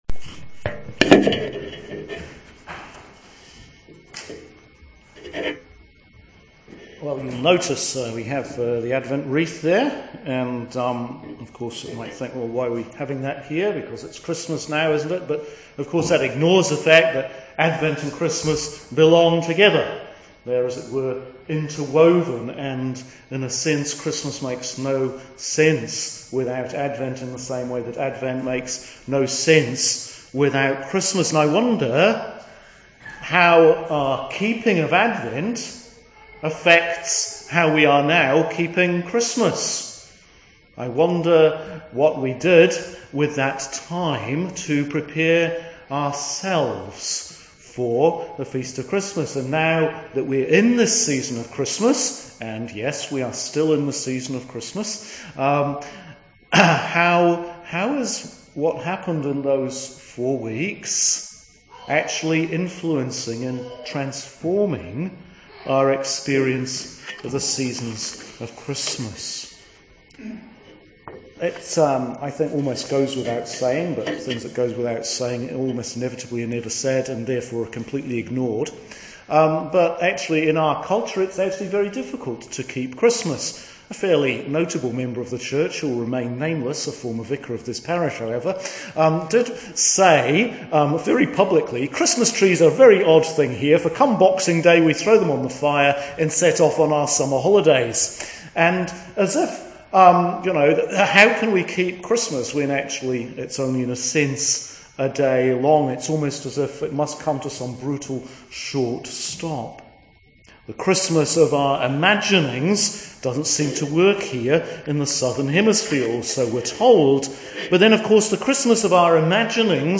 Sermon for Christmas 1 – Year A – Keeping Christmas
Sermon for Sunday December 29th – First of Christmas – Year A